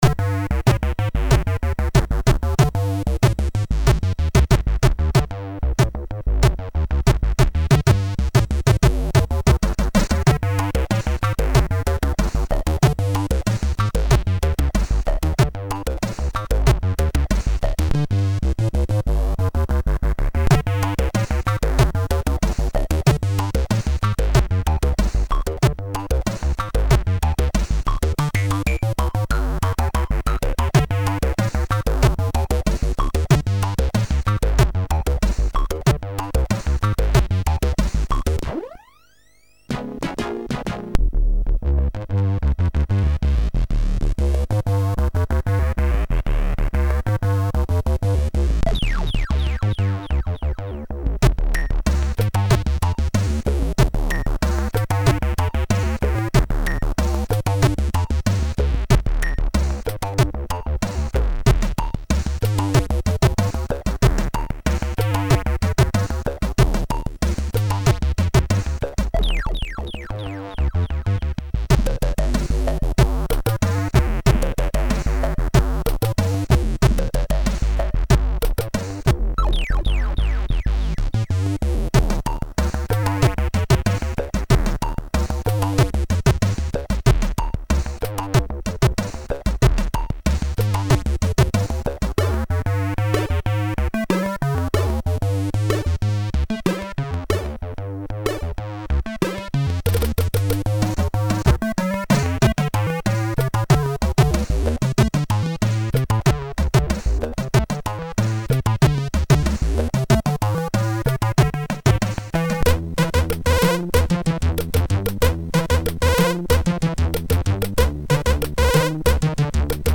Music, as played by SIDMan